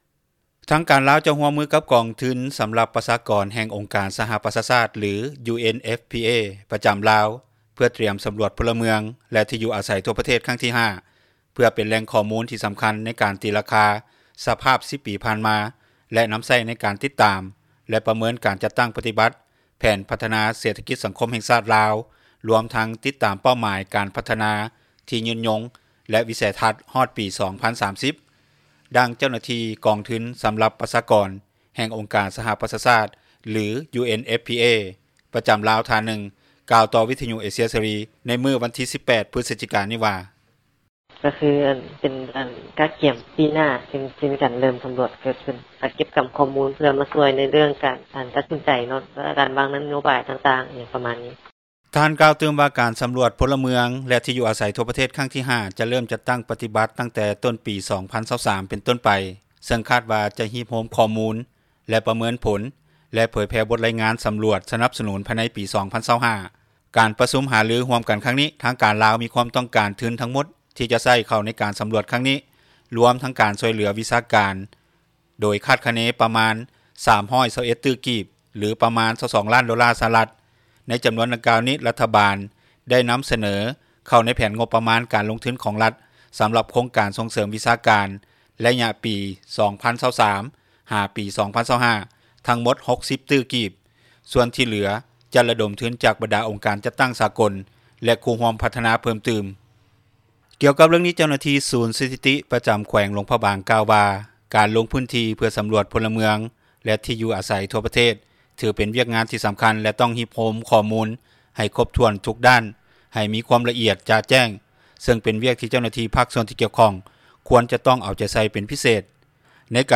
ດັ່ງເຈົ້າໜ້າທີ່ ທ່ານນີ້ກ່າວ ຕໍ່ວິທຍຸເອເຊັຽ ເສຣີ ໃນມື້ດຽວກັນນີ້ວ່າ:
ດັ່ງຊາວໜຸ່ມລາວ ຜູ້ນີ້ກ່າວຕໍ່ວິທຍຸ ເອເຊັຽເສຣີ ໃນມື້ດຽວກັນນີ້ວ່າ: